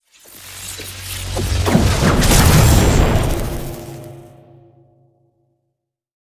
TELEPORT2.wav